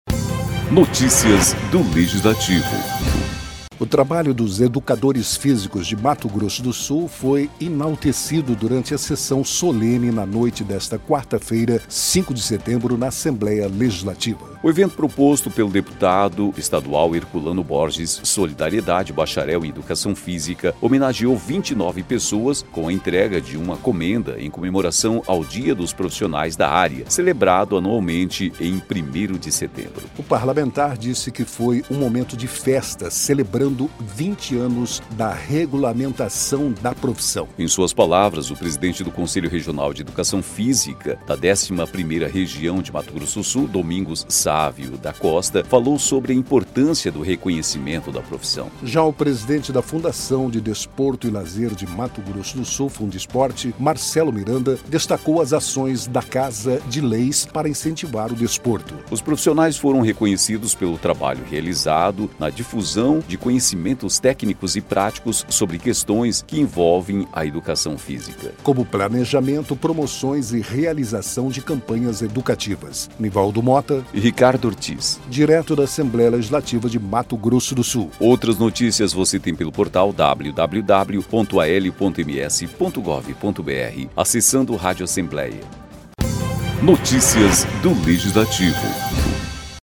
O trabalho dos educadores físicos de Mato Grosso do Sul foi enaltecido durante sessão solene na noite desta quarta-feira (5) na Assembleia Legislativa.